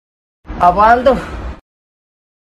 Kategori: Suara viral
Keterangan: Efek Suara Meme 'Apaan Tuh' sangat populer untuk meme dan video lucu. Sound Effect ini berdurasi 1 detik dan sering digunakan dalam edit video untuk menambah kesan humor.